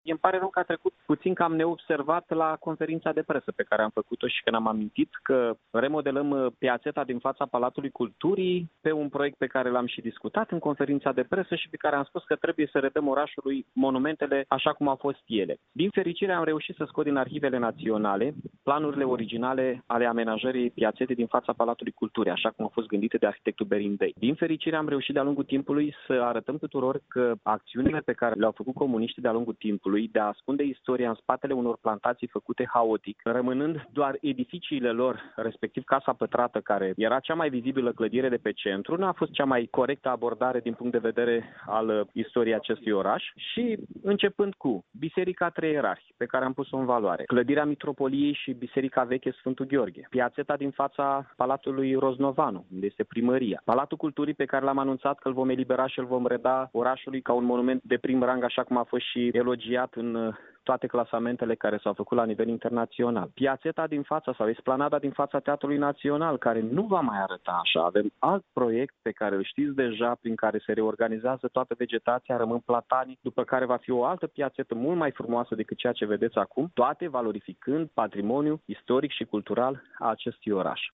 Primarul Mihai Chirica a declarat, pentru postul nostru de radio, că acţiunea a fost anunţată într-o conferinţă de presă şi face parte dintr-o campanie a muncipalităţii de punere în valoare a monumentelor istorice ale Iaşului: